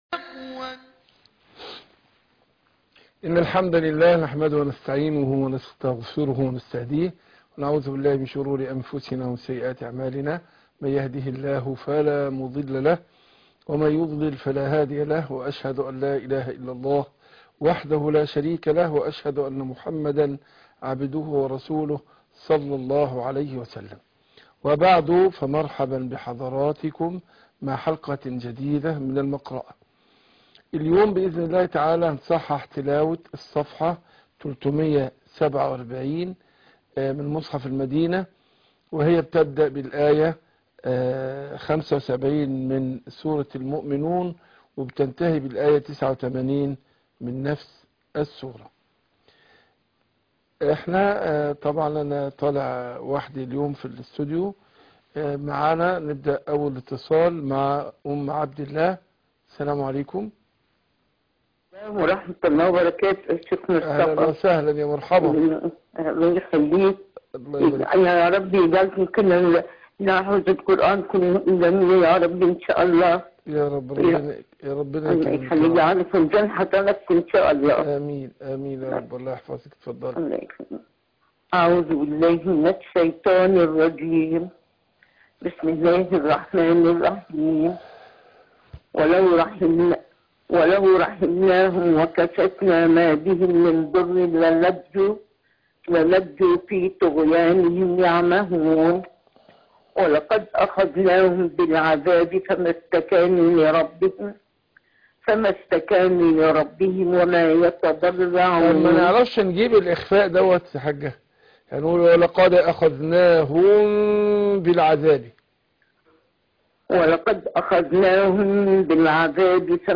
المقرأة - سورة المؤمنون ص 347